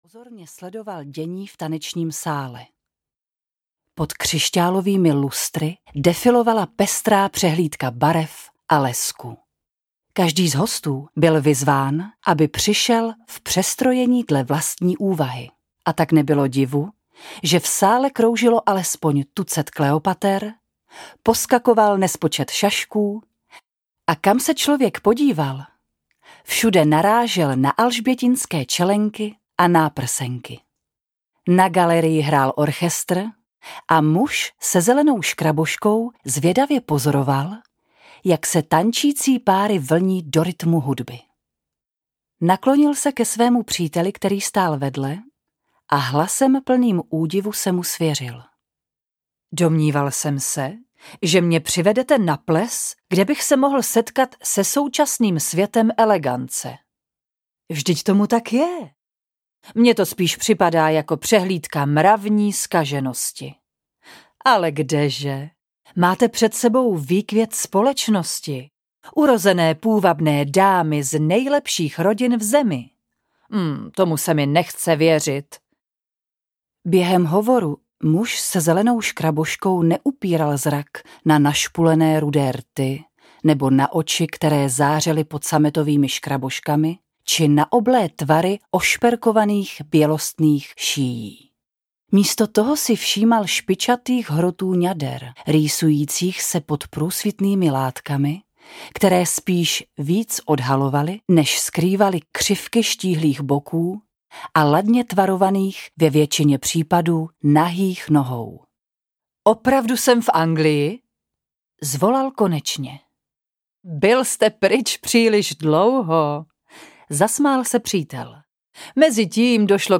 Nezkrotná Lorinda audiokniha
Ukázka z knihy